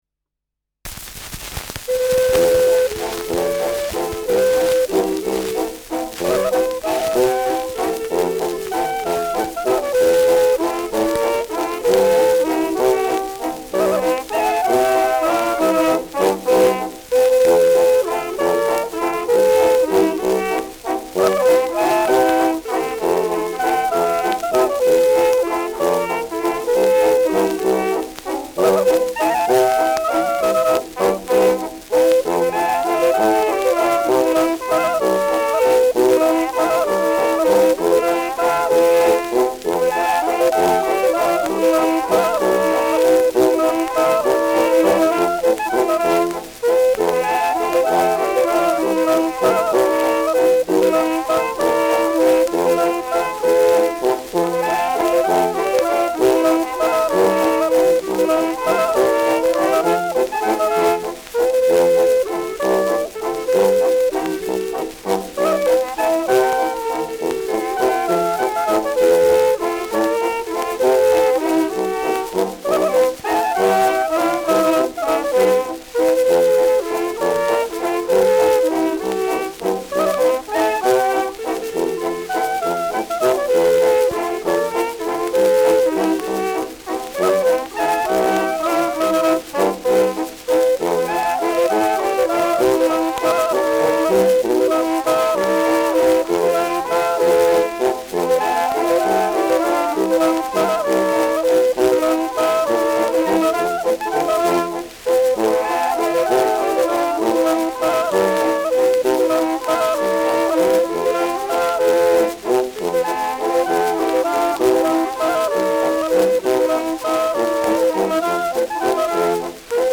Schellackplatte
präsentes Rauschen
Kapelle Die Alten, Alfeld (Interpretation)
[Nürnberg?] (Aufnahmeort)